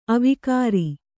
♪ avikāri